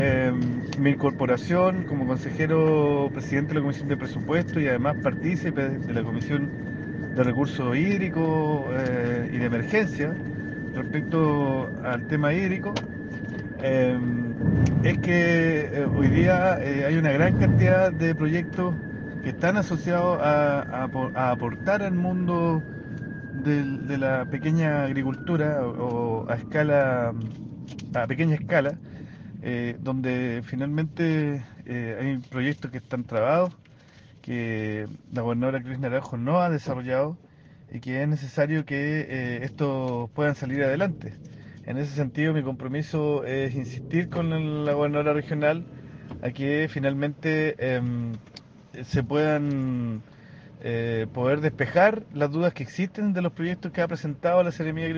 Ante esta situación de emergencia, diferentes autoridades llegaron hasta la localidad de El Palqui, con el fin de dialogar con los afectados y buscar soluciones concretas.
El consejero Javier Vega, como representante del Gobierno Regional, expuso que